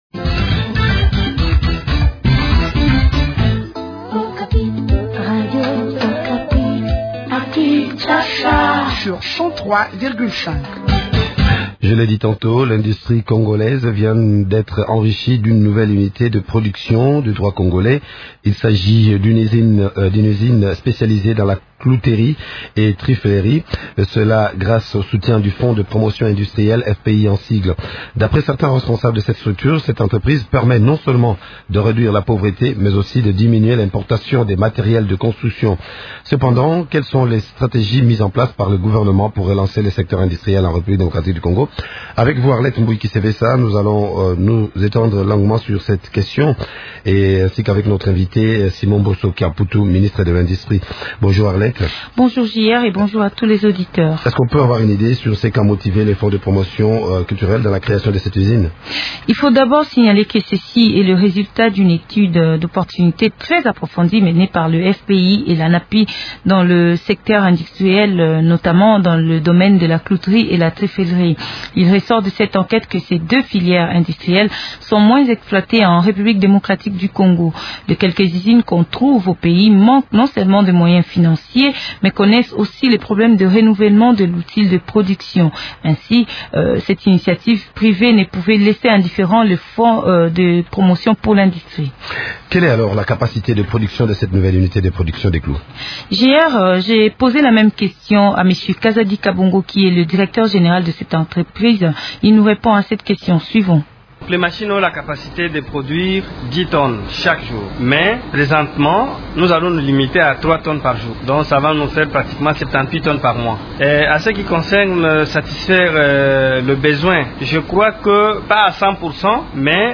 ministre nationale de l’industrie.